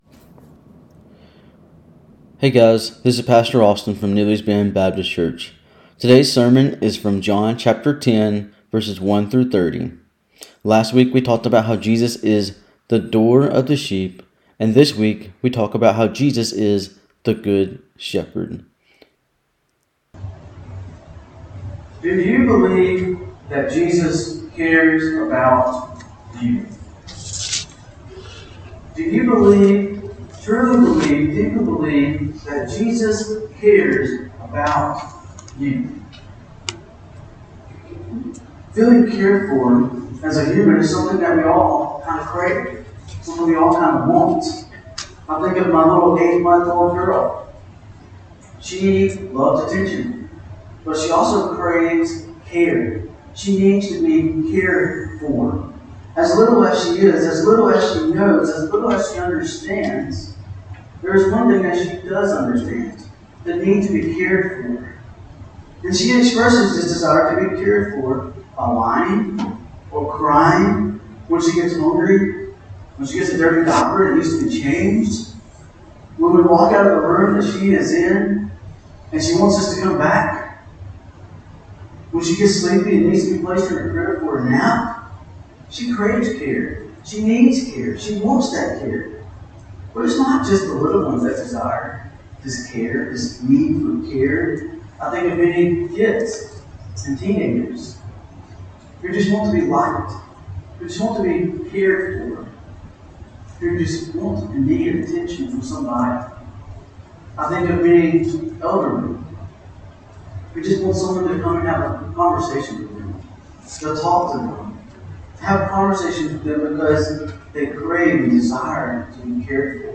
Sermons | Neely's Bend Baptist Church